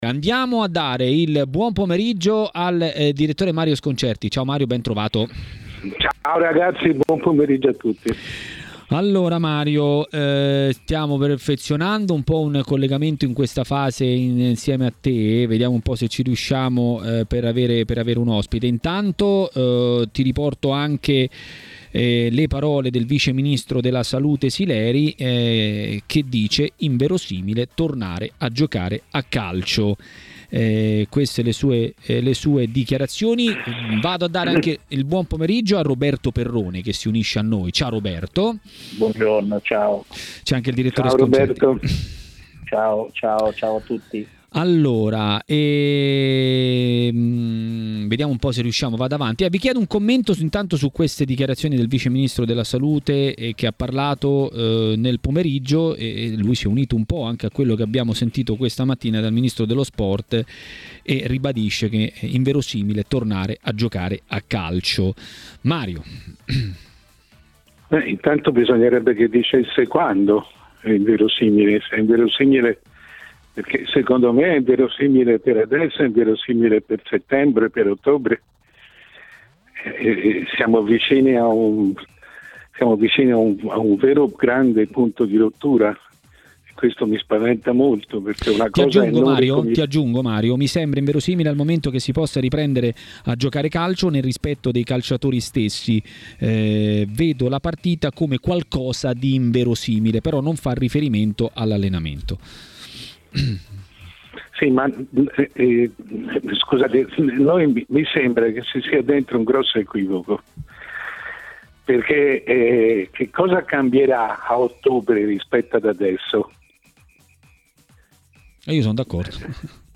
Il futuro della Serie A, lo spettro di una chiusura anticipata. Di tutto questo ha parlato a TMW Radio, durante Maracanà, il presidente dell'Aia (Associazione Italiana Arbitri) Marcello Nicchi.